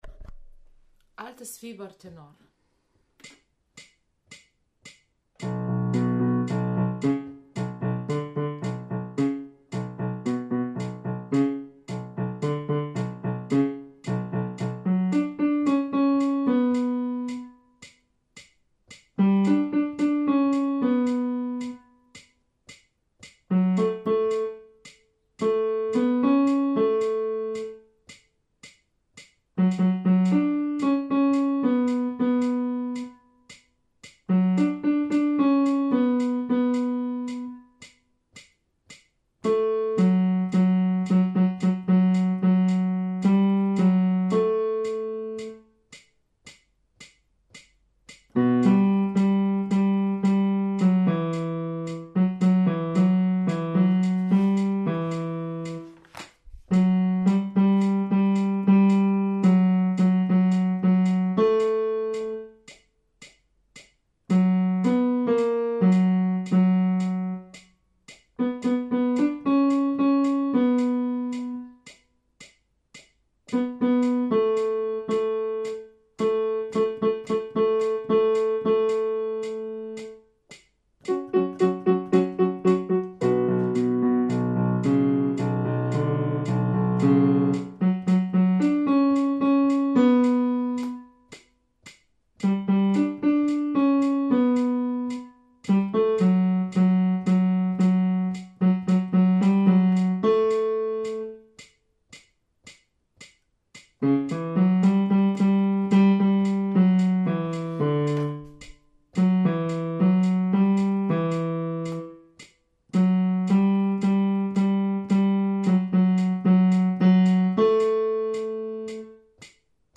Altes-Fieber-Tenor.mp3